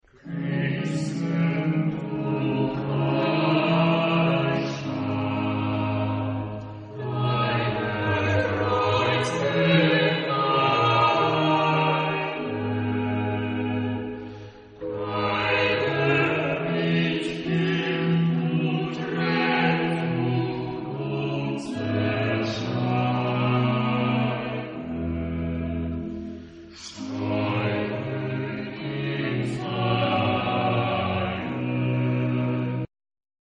Arr.: Bach, Johann Sebastian (1685-1750) [ Germany ]
Genre-Style-Form: Chorale ; Sacred ; Baroque
Type of Choir: SATB  (4 mixed voices )
Tonality: D minor